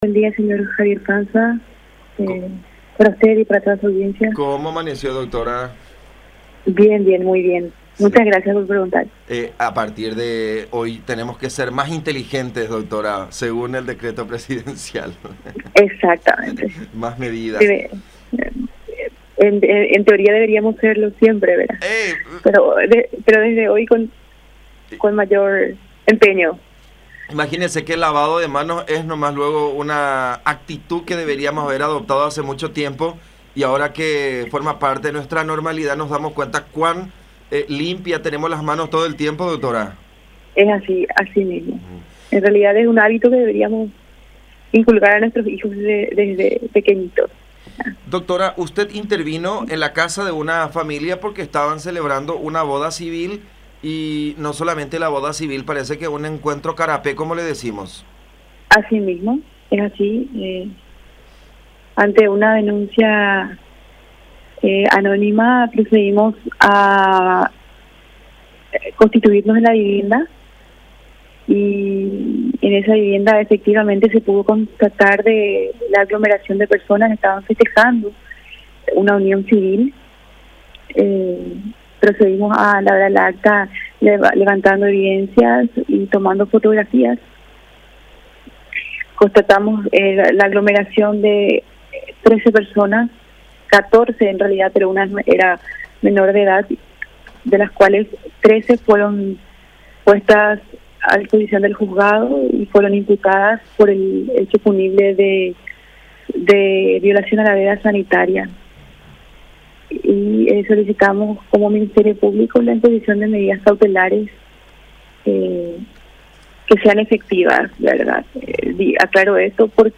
“Nos constituimos en la vivienda cerca de las 14:30 aproximadamente y pudimos constatar la aglomeración de 14 personas (incluyendo una menor de edad)”, detalló la fiscal Myriam Rodríguez, interviniente en el caso, en diálogo con La Unión.